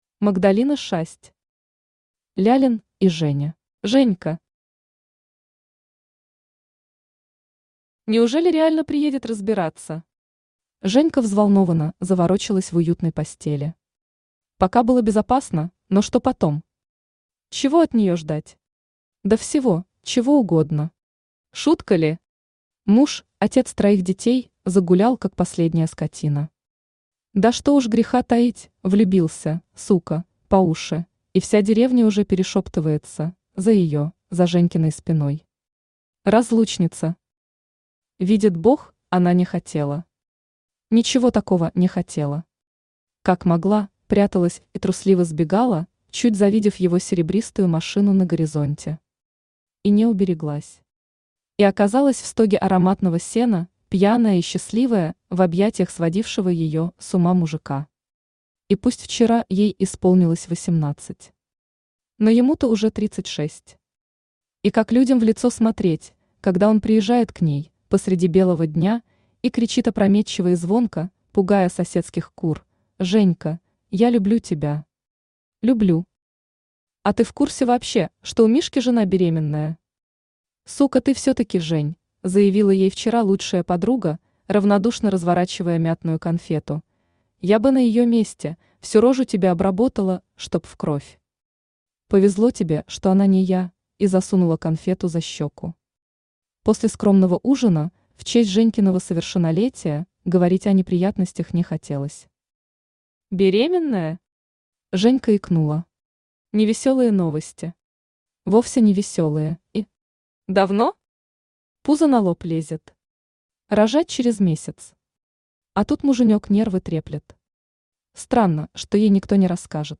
Аудиокнига Лялин и Женя | Библиотека аудиокниг